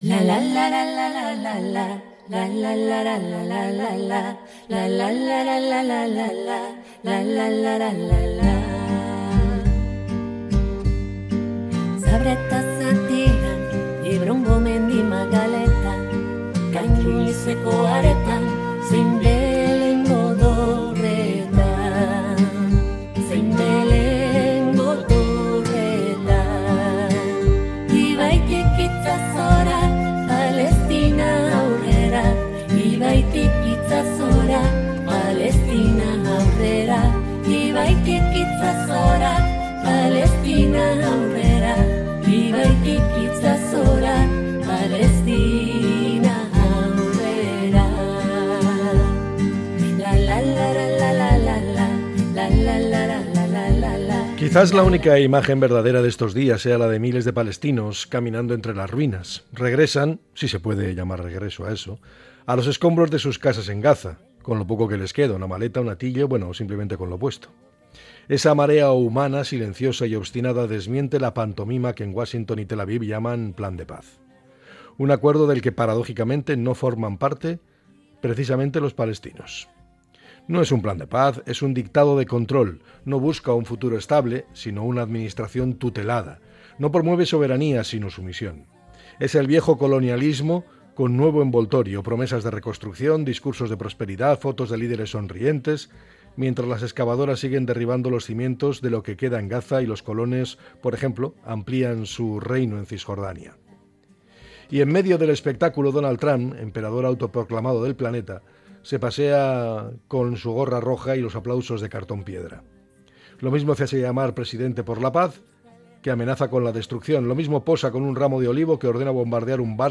El comentario
Podcast Opinión